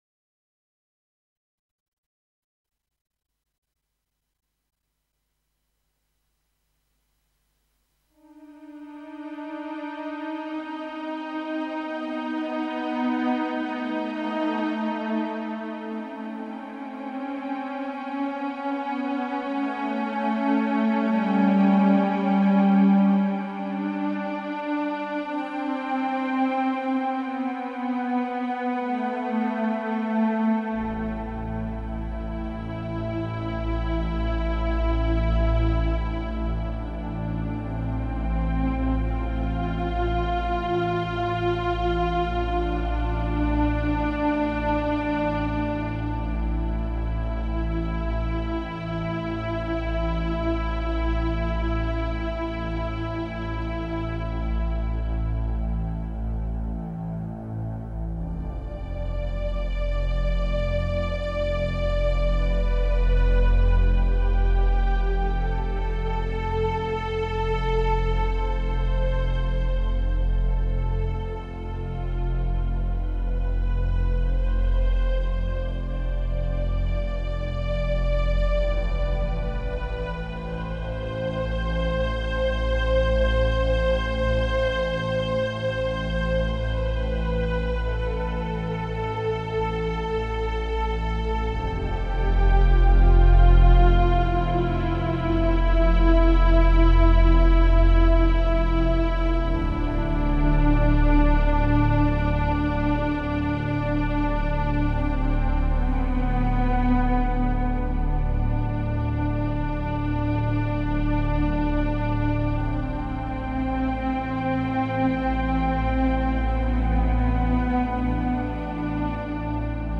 Diese warmen (ein wenig depressiven) Klänge kommen aus dem
GEM S2 Turbo.
gem_pad.mp3